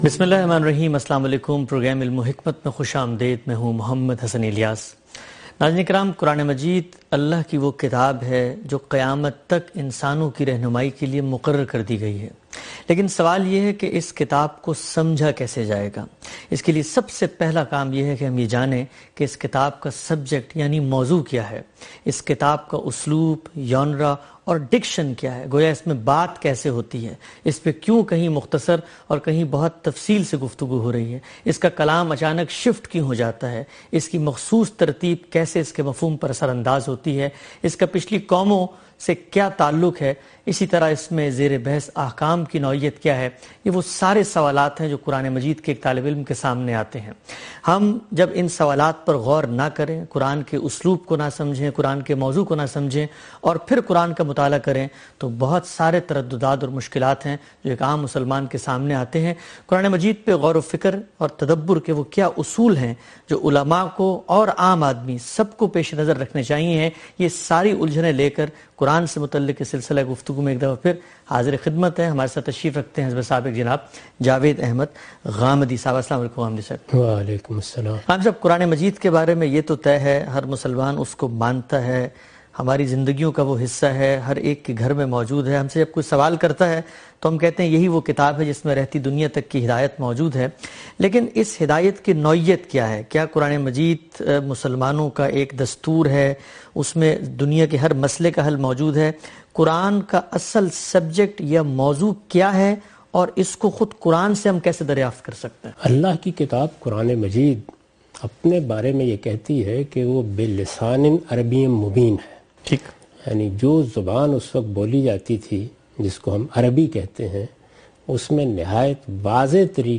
In this program Javed Ahmad Ghamidi answers questions about "Theme of the Quran".